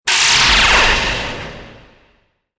دانلود آهنگ نبرد 18 از افکت صوتی انسان و موجودات زنده
جلوه های صوتی
دانلود صدای نبرد 18 از ساعد نیوز با لینک مستقیم و کیفیت بالا